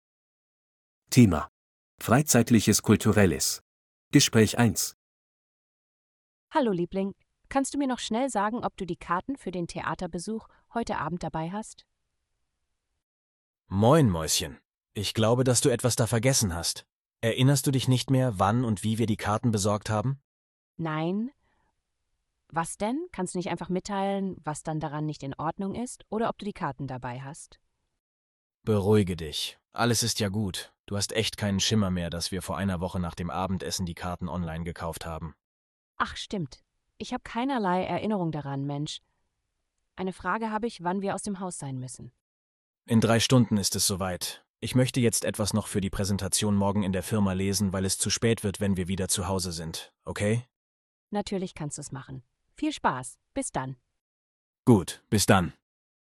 Audio text conversation 1:
A2-Kostenlose-R-Uebungssatz-3-Freizeitliches-Kulturelles-Gespraech-1.mp3